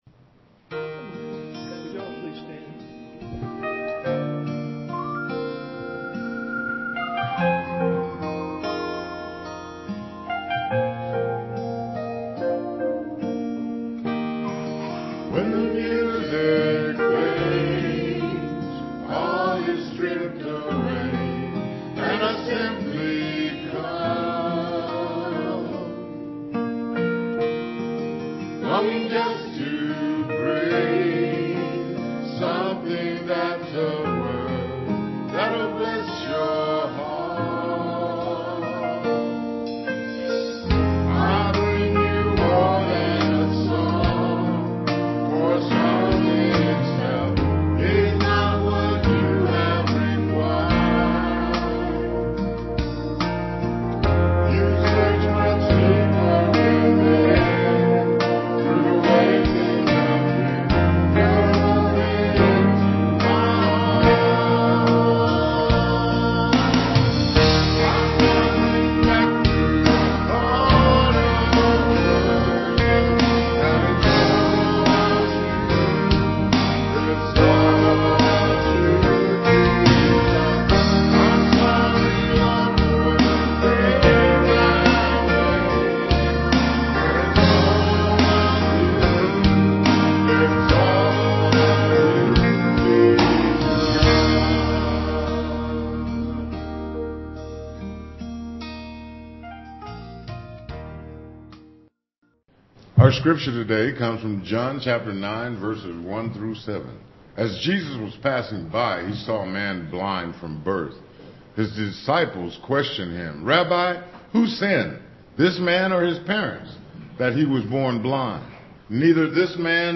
Piano and organ duet
Solo